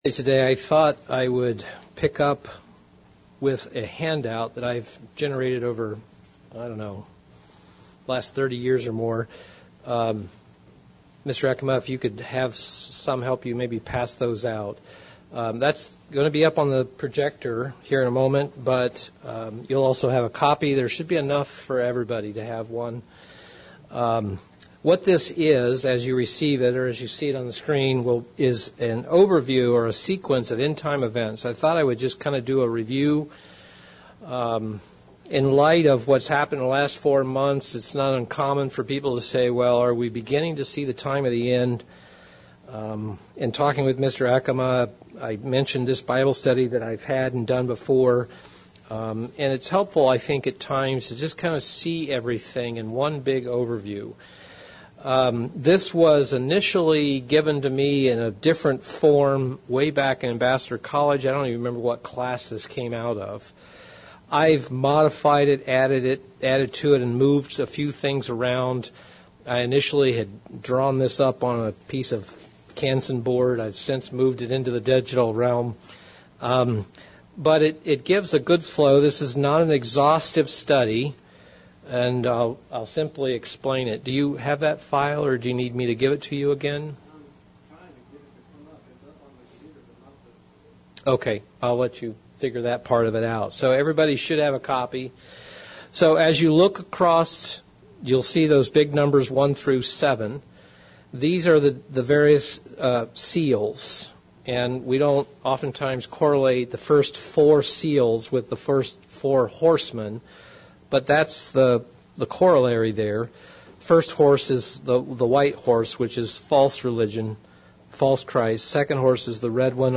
End Time Events-Bible Study